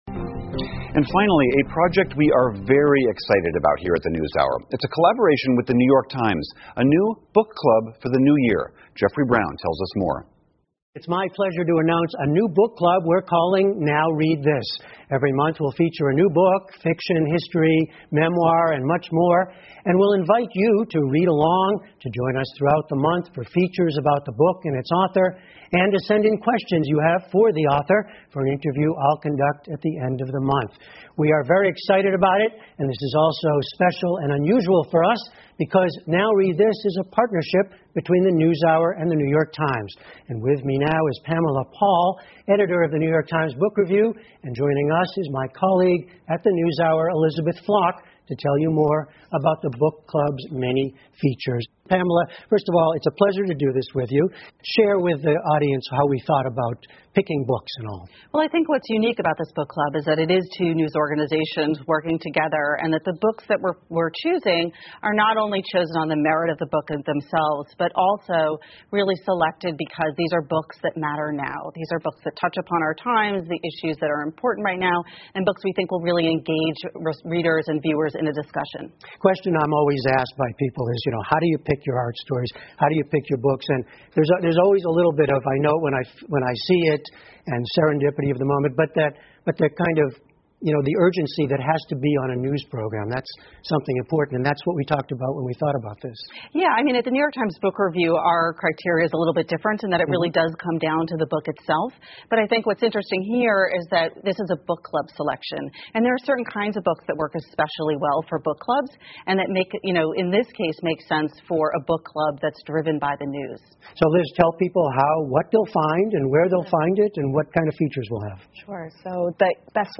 PBS高端访谈:介绍PBS NewsHour纽约时报读书俱乐部 听力文件下载—在线英语听力室